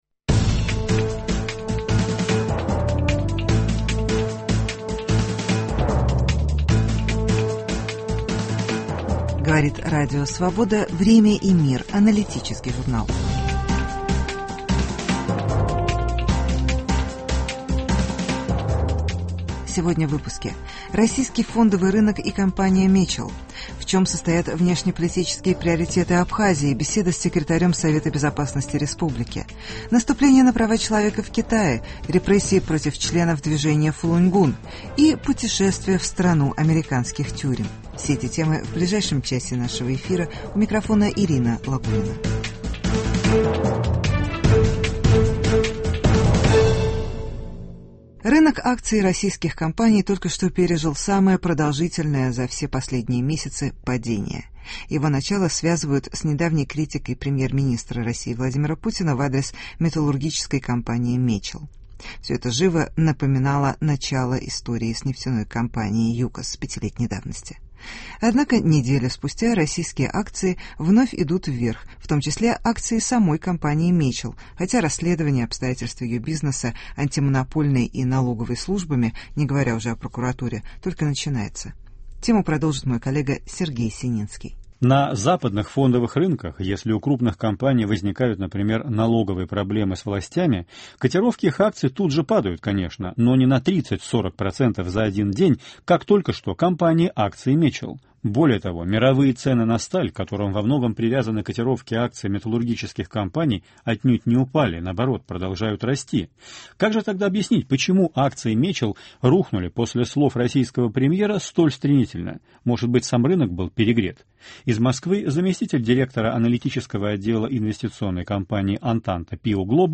В чем состоят внешнеполитические приоритеты Абхазии. Беседа с секретарем Совета безопасности республики.